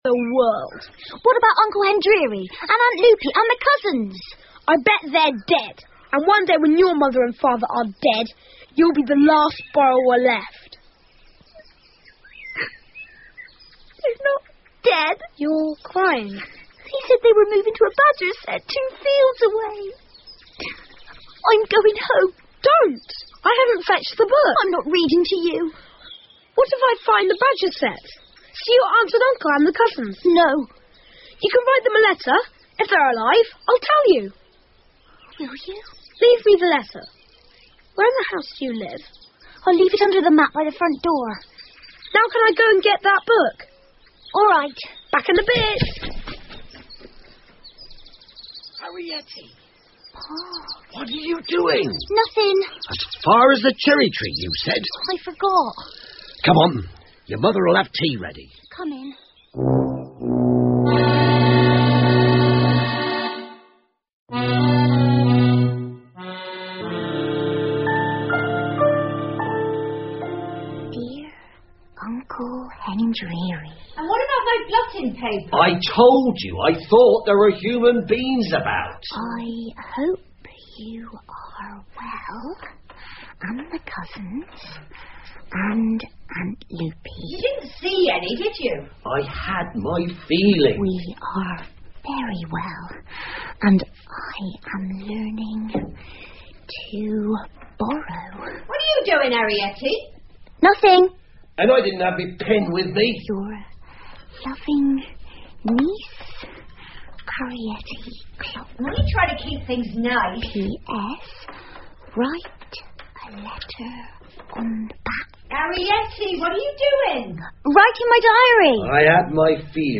借东西的小人 The Borrowers 儿童广播剧 6 听力文件下载—在线英语听力室